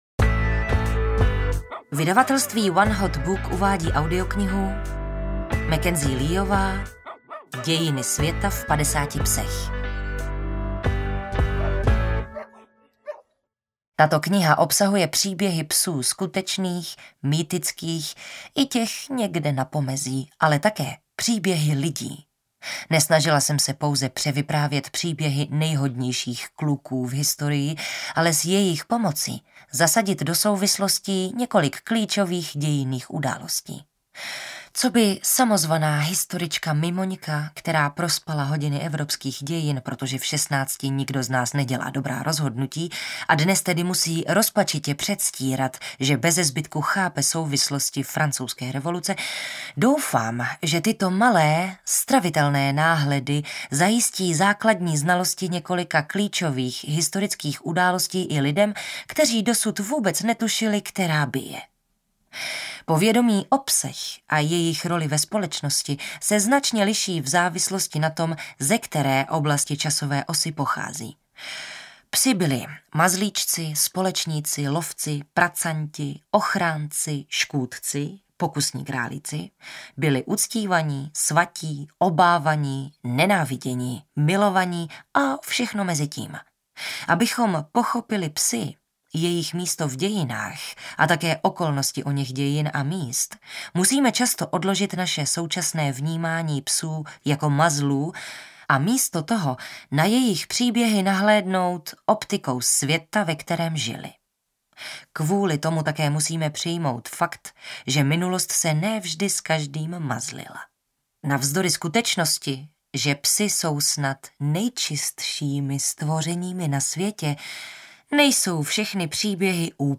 Dějiny světa v 50 psech audiokniha
Ukázka z knihy